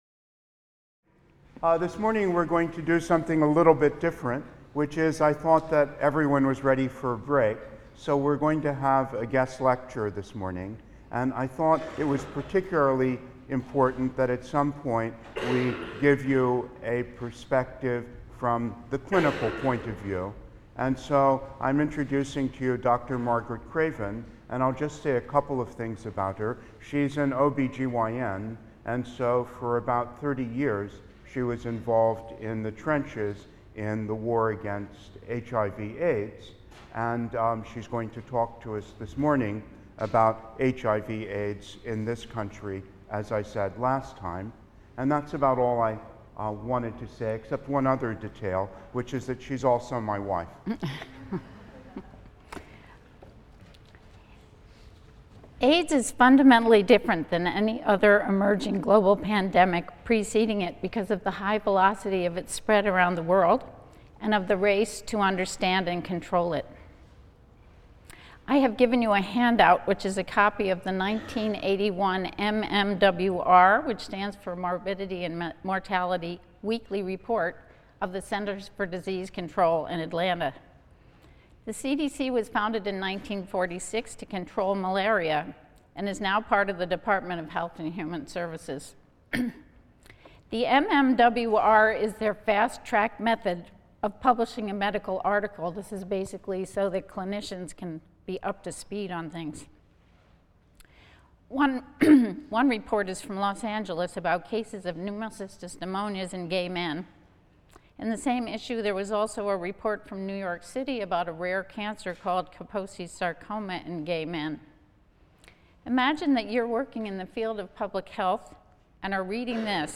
HIST 234 - Lecture 23 - AIDS (II) | Open Yale Courses